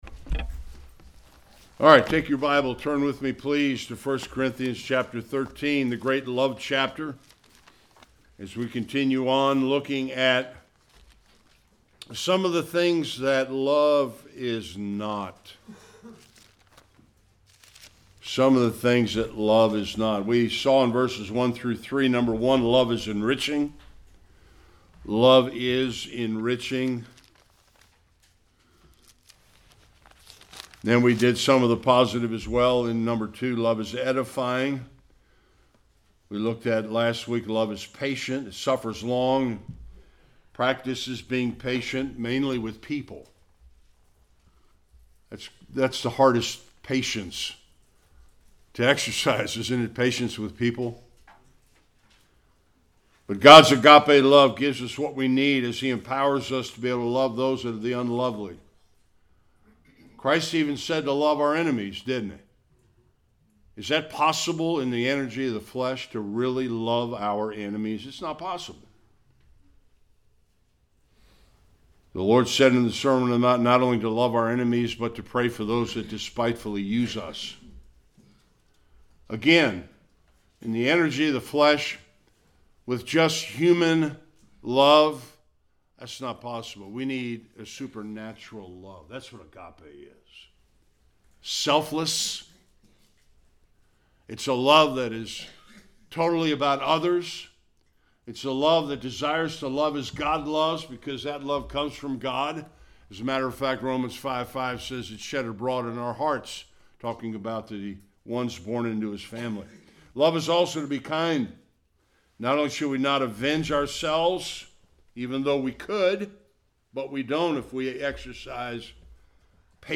4-5 Service Type: Sunday Worship Agape love